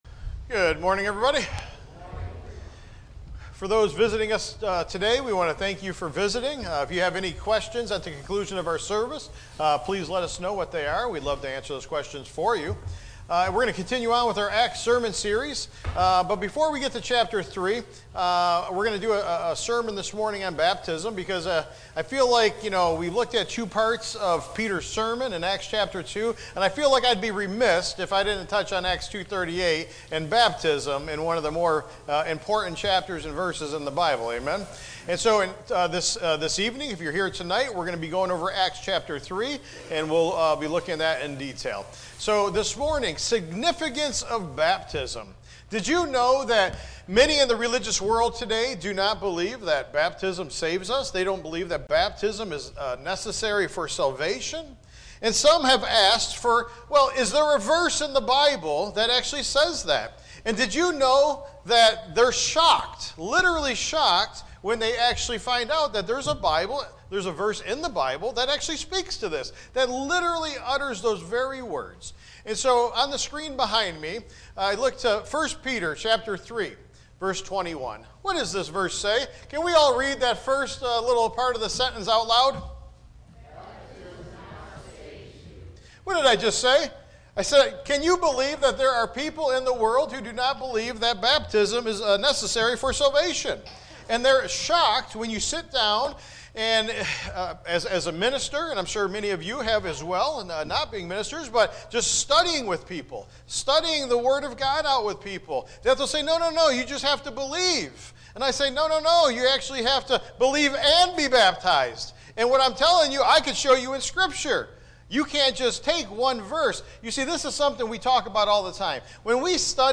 Tagged with sermon